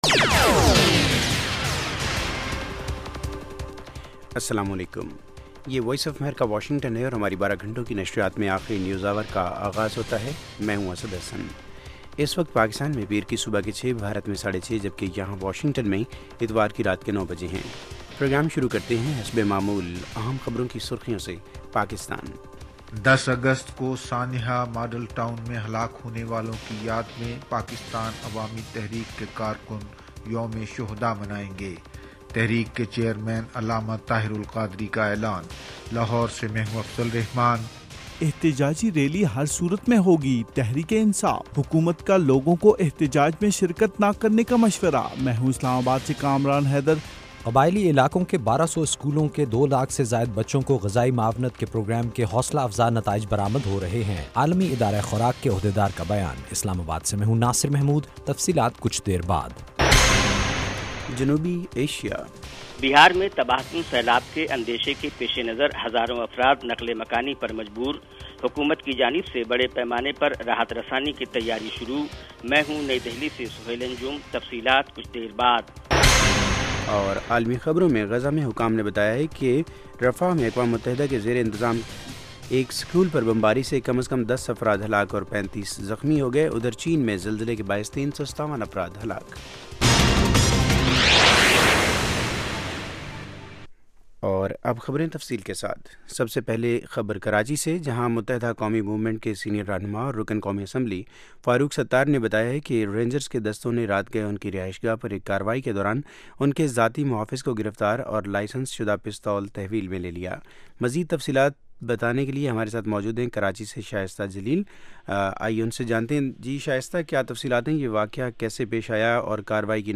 اس ایک گھنٹے کے پروگرام میں دن بھر کی اہم خبریں اور پاکستان اور بھارت سے ہمارے نمائندوں کی رپورٹیں پیش کی جاتی ہیں۔ اس کے علاوہ انٹرویو، صحت، ادب و فن، کھیل، سائنس اور ٹیکنالوجی اور دوسرے موضوعات کا احاطہ کیا جاتا ہے۔